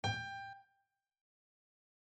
G_SOL.mp3